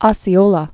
(ŏsē-ōlə, ōsē-) 1804?-1838.